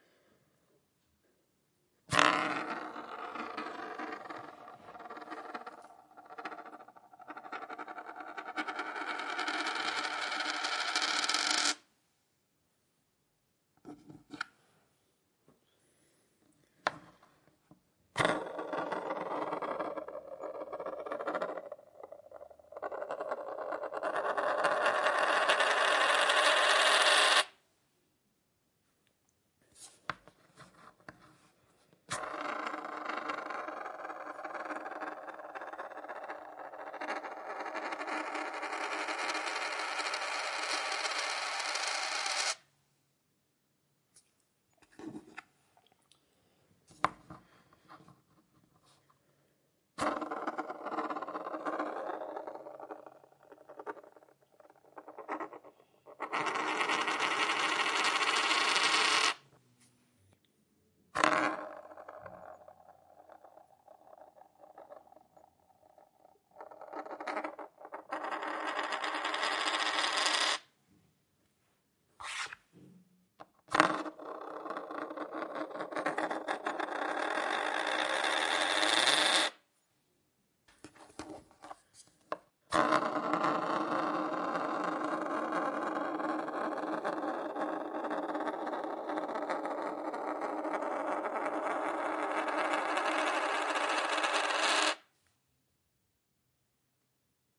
硬币在盘子上滚动
描述：用手指给瓷盘上的硬币一个快速旋转的冲动，让它独自旋转，直到最后掉下来。
标签： 硬币 金属 纺纱 车削 硬币 扭曲 瓷器 滚动
声道立体声